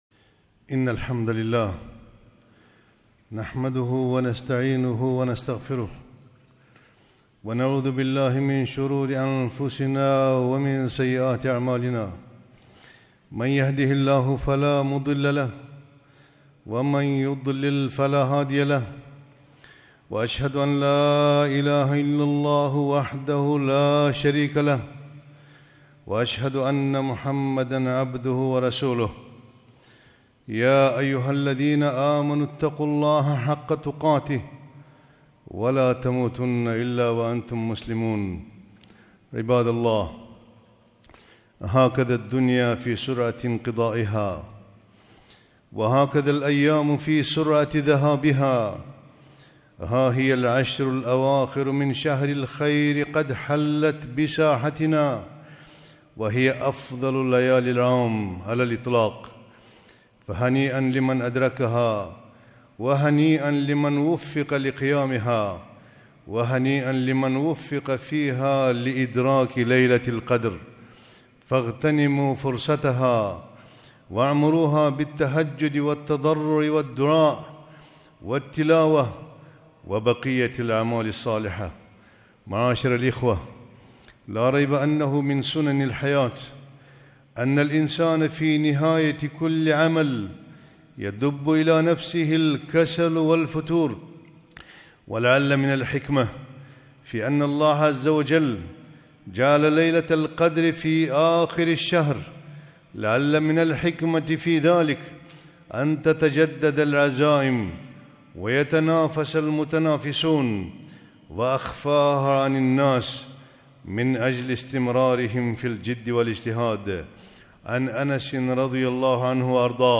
خطبة - ليالي العشر غنيمة وأجر